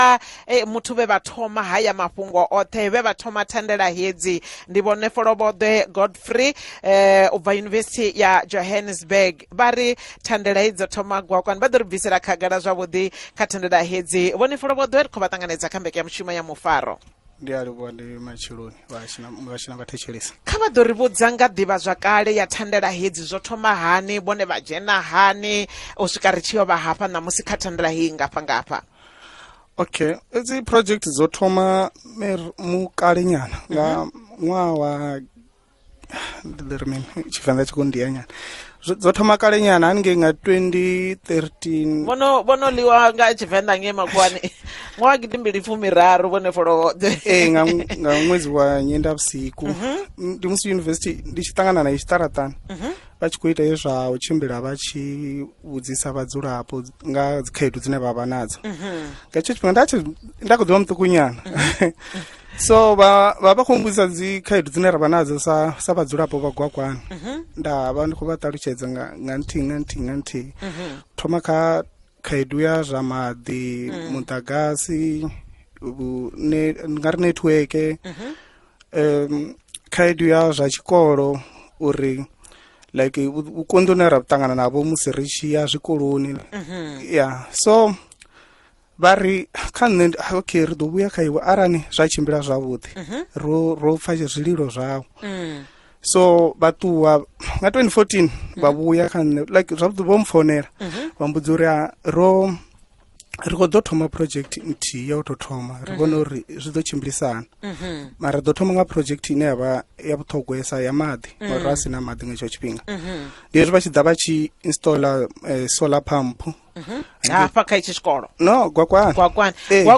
5 Sep Interview on PhalaphalaFM - part 6